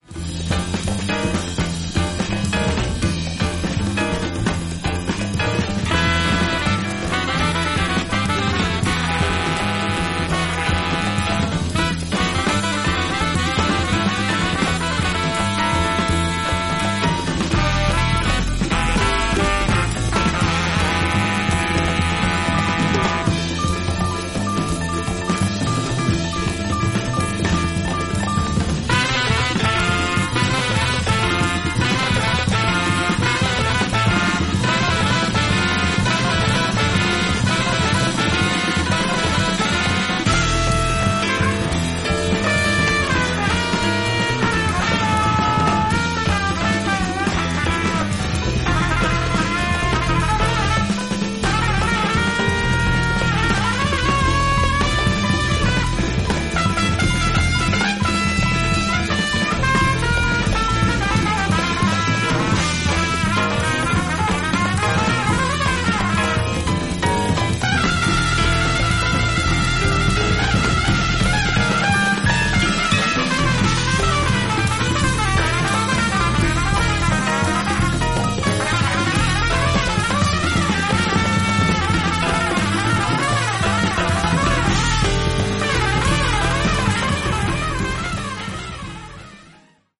フリーキーなサックスと鍵盤の絡みがクールな